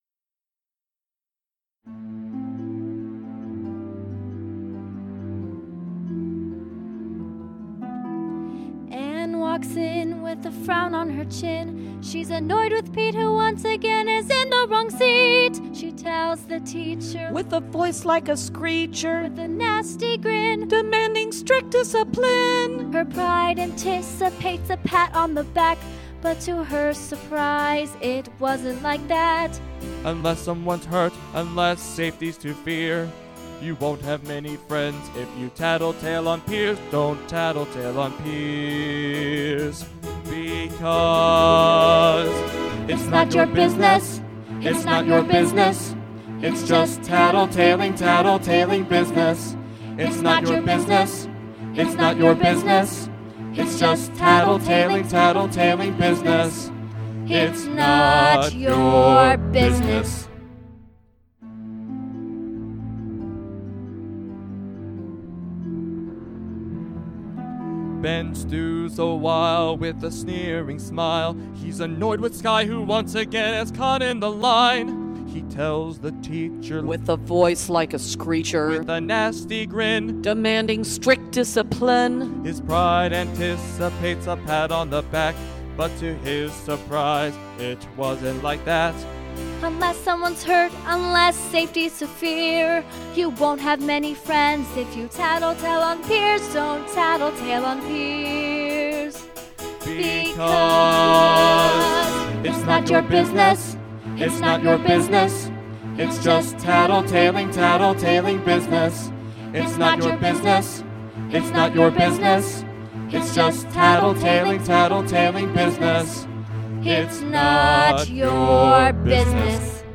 MP3 (with singing)